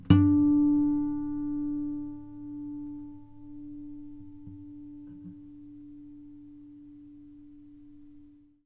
harmonic-01.wav